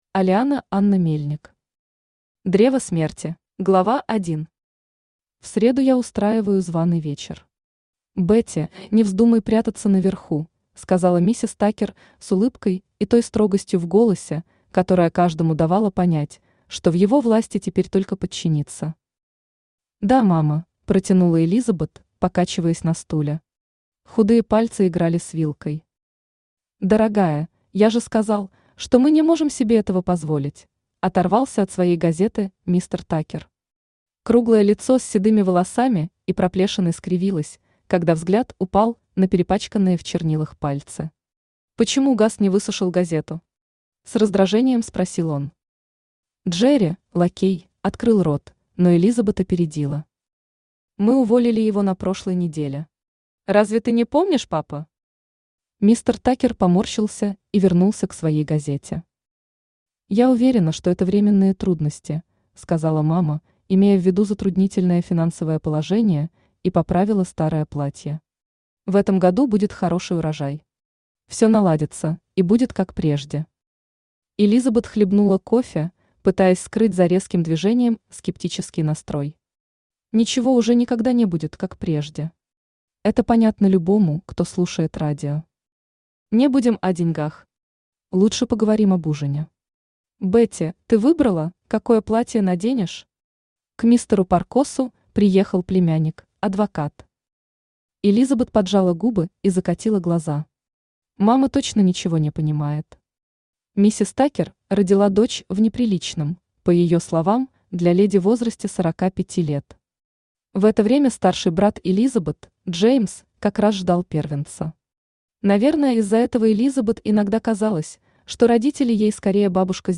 Аудиокнига Древо смерти | Библиотека аудиокниг
Aудиокнига Древо смерти Автор Алиана-Анна Мельник Читает аудиокнигу Авточтец ЛитРес.